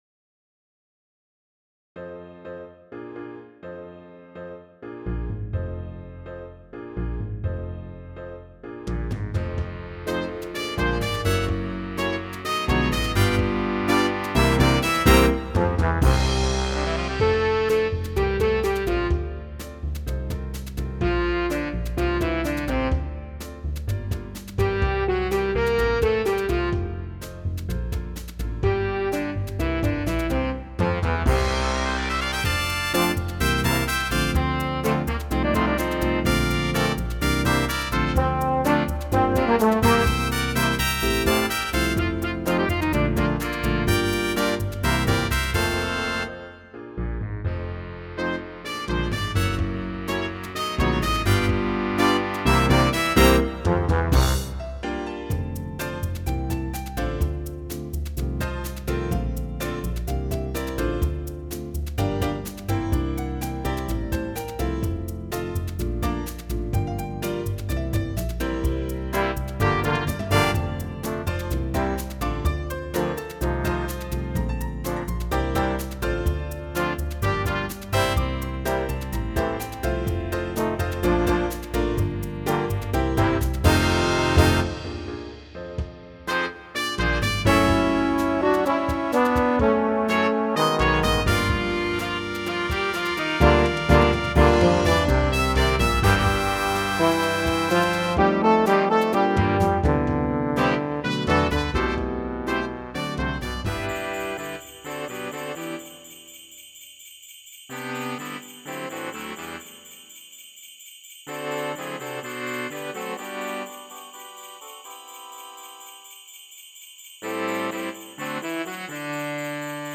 Big Band Edition
in a soul style